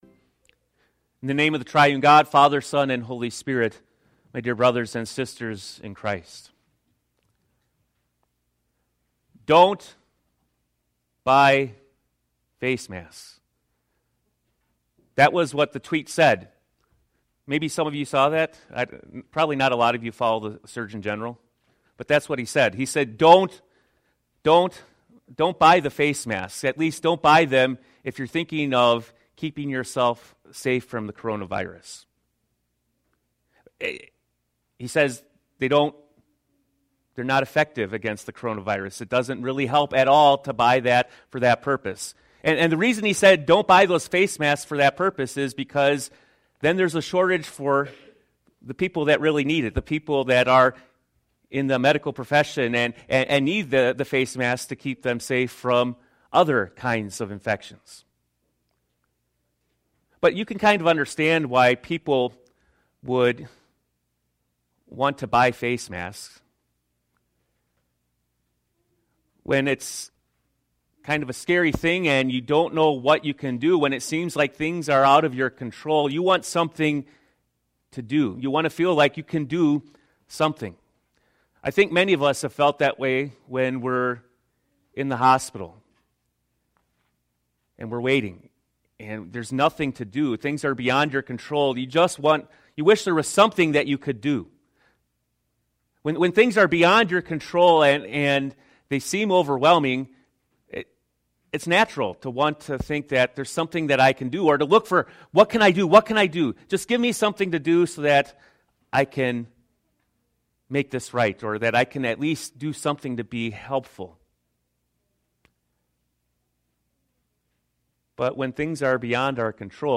Sermon-1-Mar-20.mp3